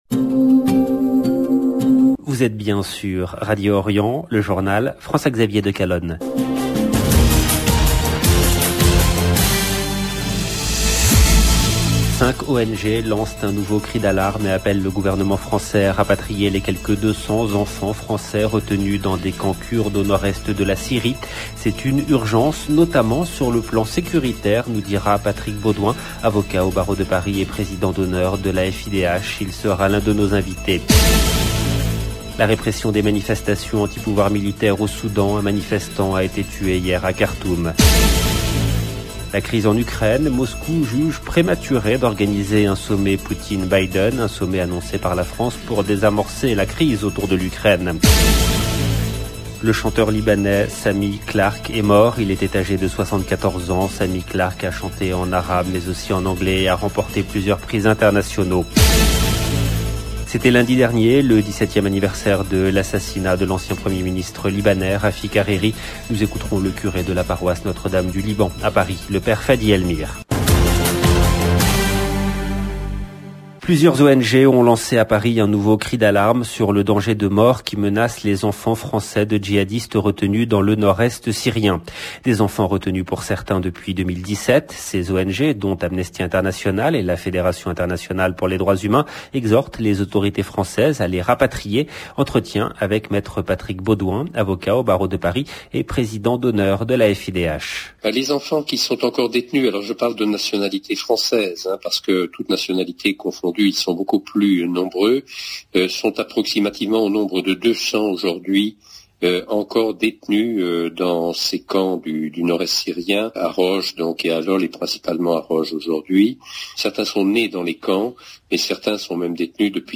LB JOURNAL EN LANGUE FRANÇAISE